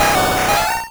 Cri de Galopa dans Pokémon Rouge et Bleu.